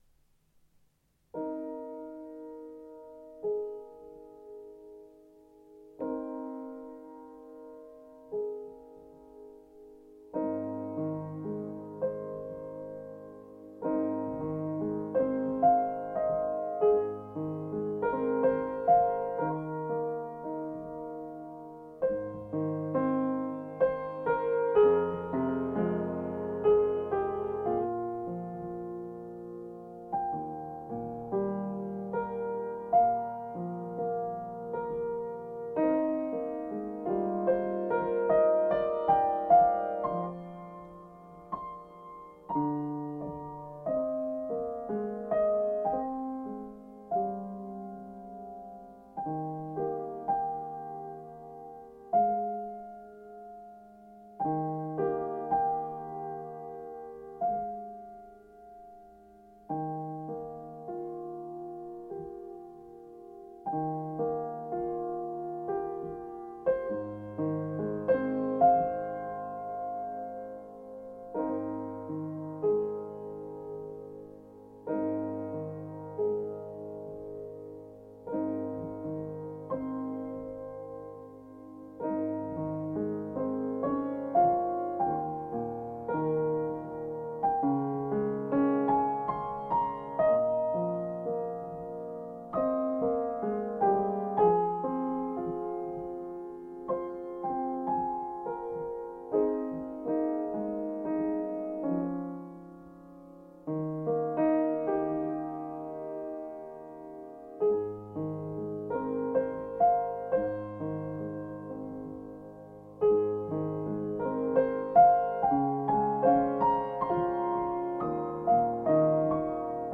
This serene little piece was the result…